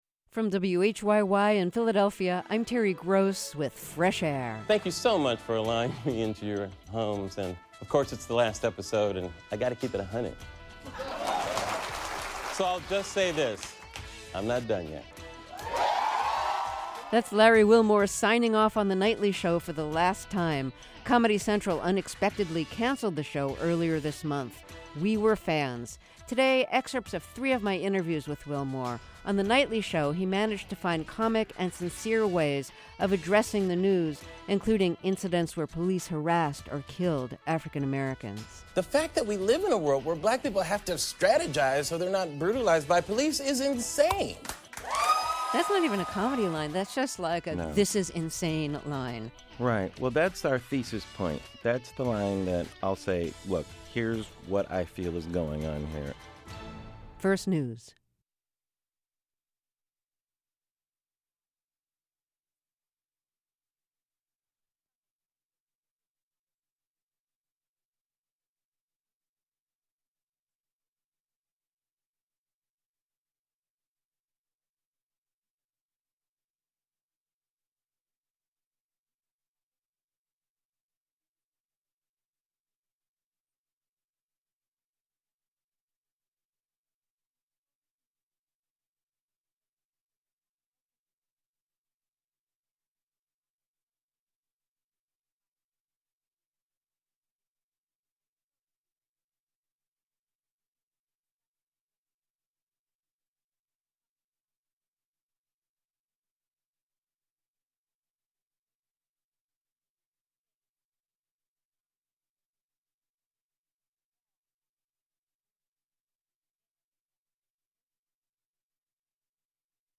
Interview John le Carré